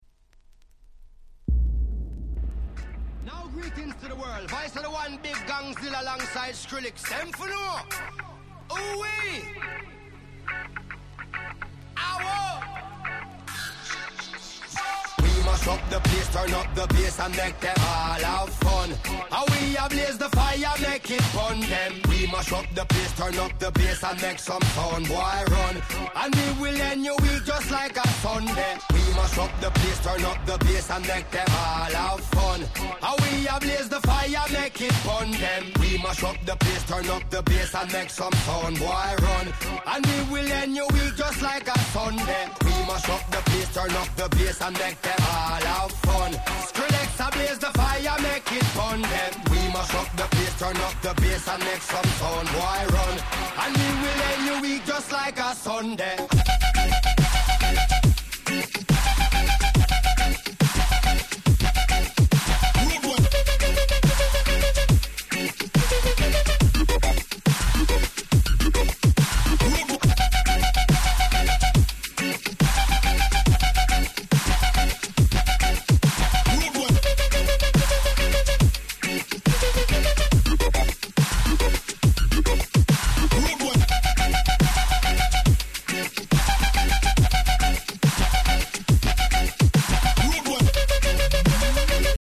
Dub Step Classics !!